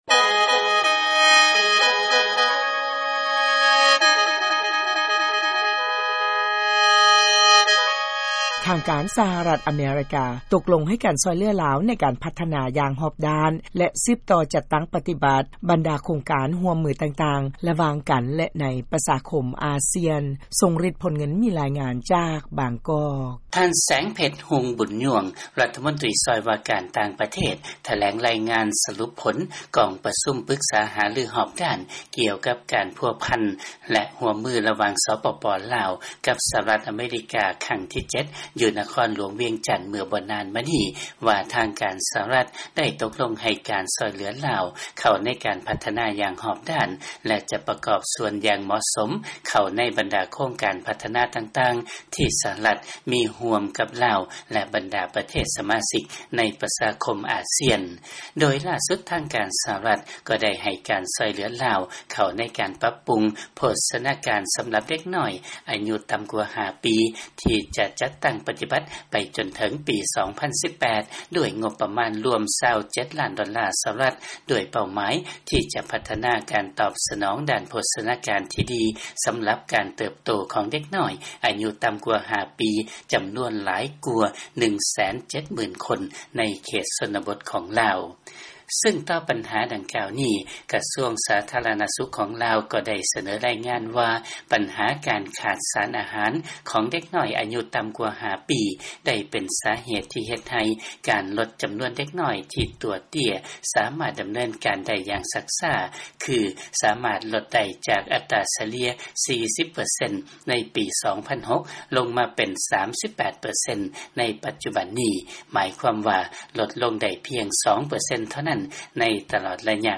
ເຊີນຟັງ ລາຍງານ ສະຫະລັດ ຕົກລົງໃຫ້ ການຊ່ວຍເຫຼືອລາວ ໃນການພັດທະນາ ຢ່າງຮອບດ້ານ.